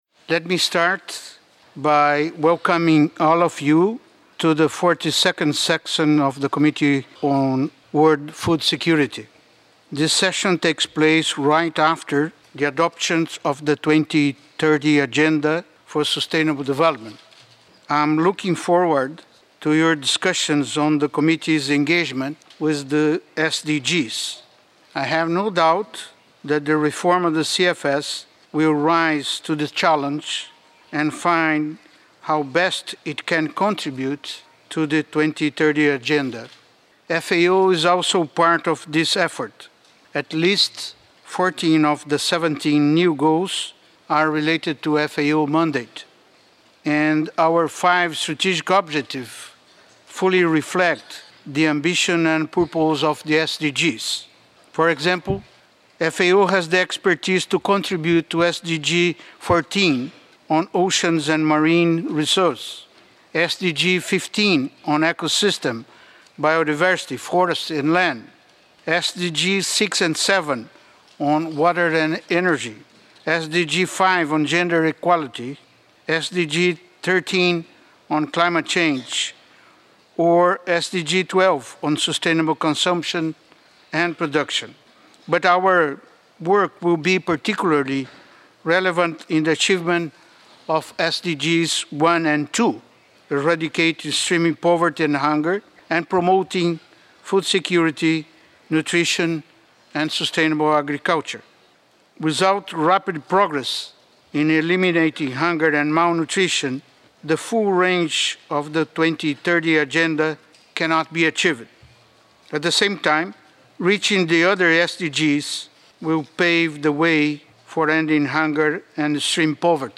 José Graziano da Silva, FAO Director-General, addresses the 42nd Session of the Committee on World Food Security.